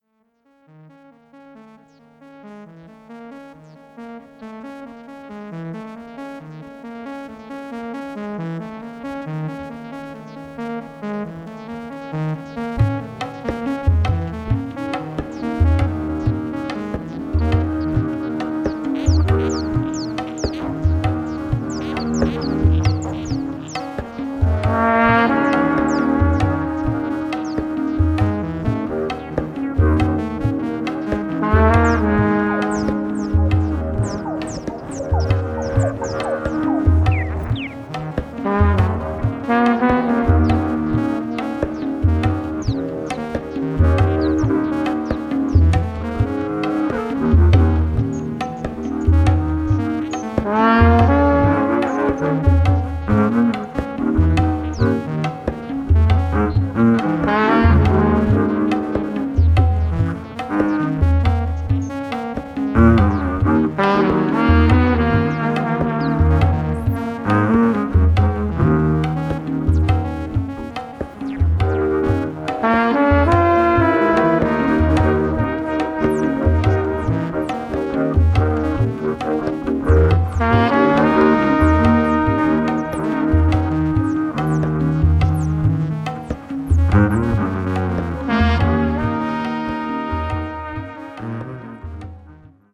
treated C trumpet, percussion, conch shell
electric & acoustic bass
tabla & djembe
moog
harp